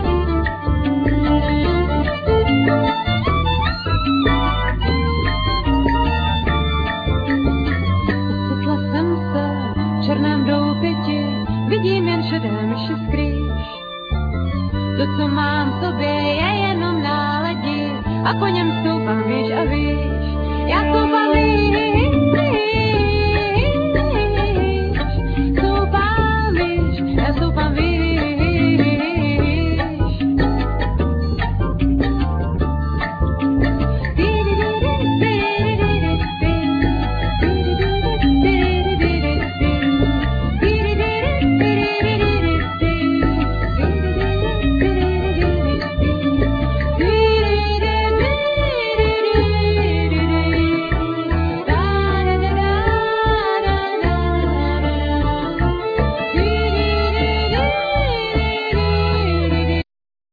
Vocal,Piano
Guitar,Vocal,Bass
Violin
Clarinet
Percussions
El.guitar